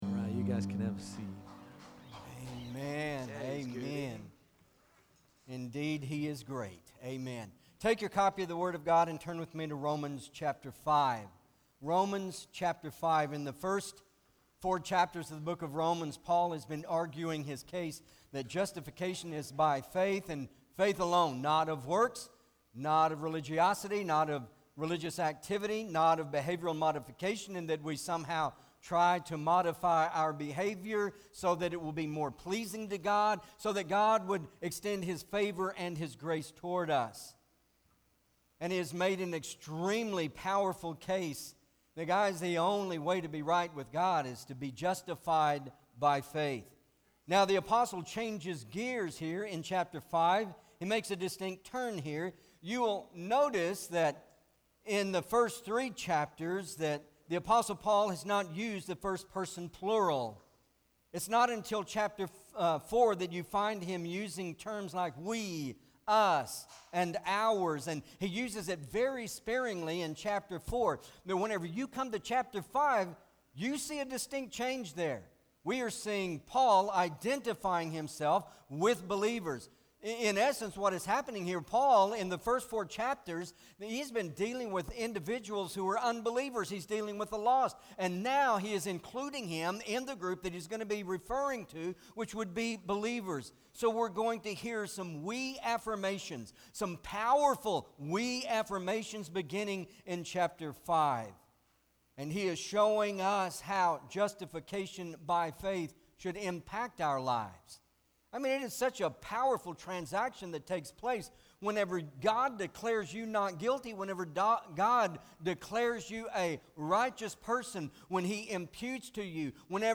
Romans Revealed- Peace With God MP3 SUBSCRIBE on iTunes(Podcast) Notes Sermons in this Series Romans 5: 1-5 Not Ashamed!